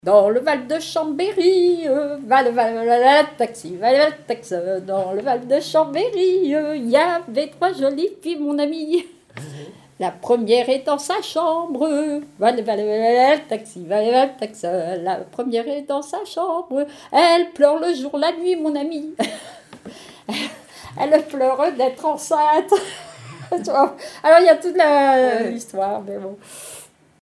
Genre laisse
Témoignages sur les chansons
Pièce musicale inédite